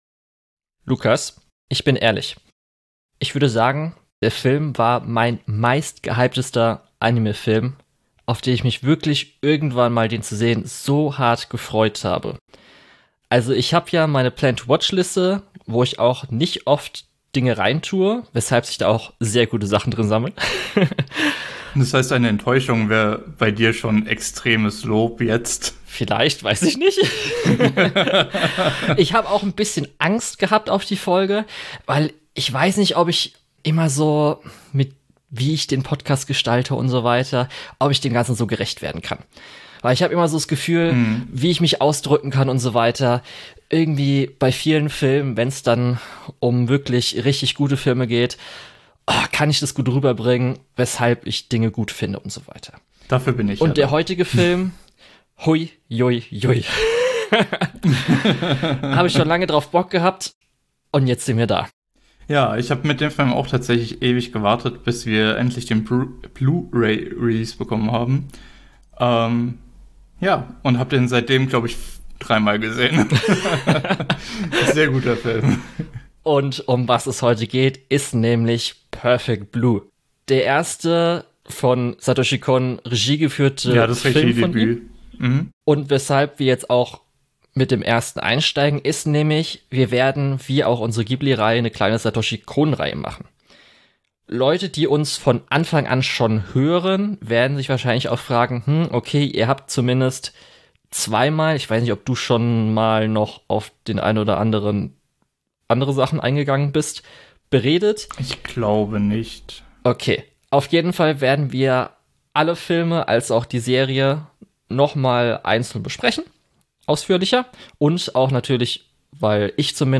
Die Begeisterung der beiden ist gleich zu Beginn der Folge spürbar und lässt im Laufe der Episode eigentlich nur ein Fazit zu: Wir haben hier ein Meisterwerk vor uns.